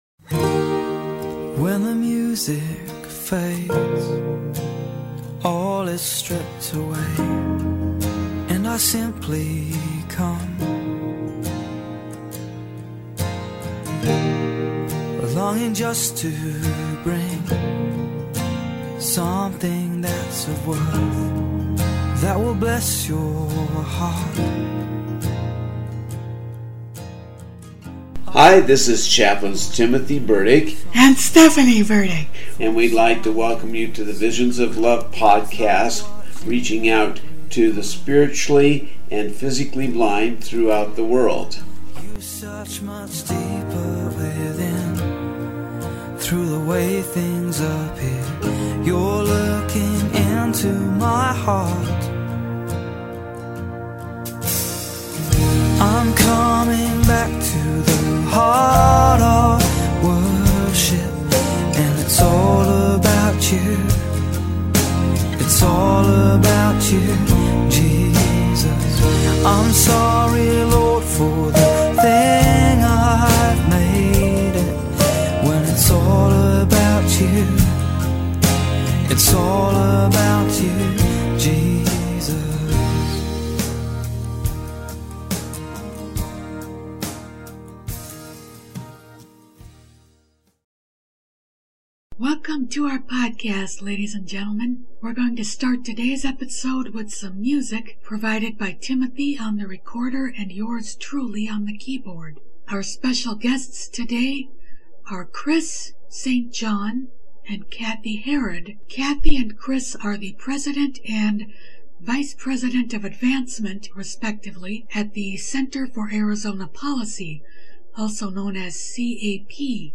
Next week we'll be recording a new interview with National Right to Life, so keep your ears open for that in the coming weeks.